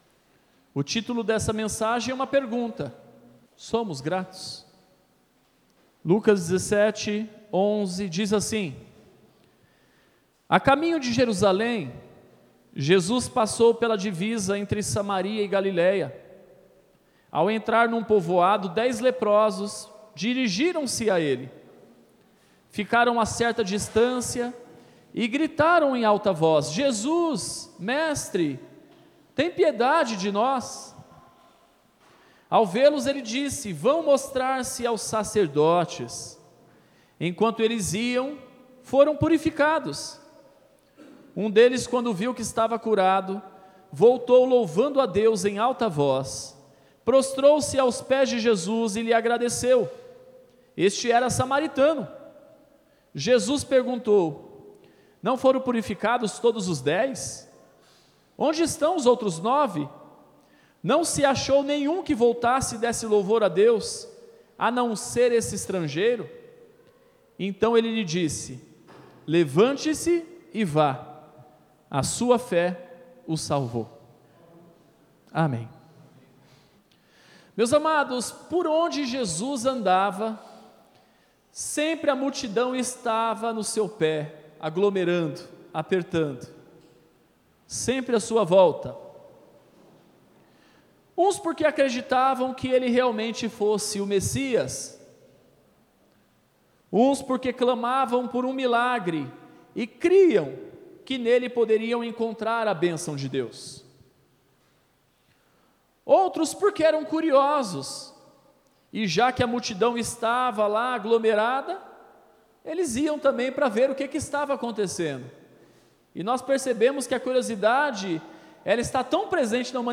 Mensagens (Podcast) Somos Gratos!